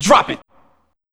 AMB147VOX-R.wav